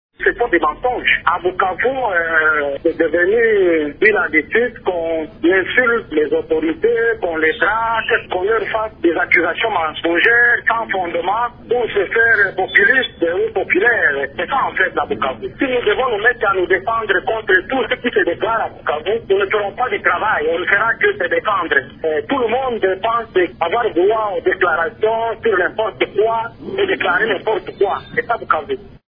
Vous pouvez écouter Meschac Bilubi  ici :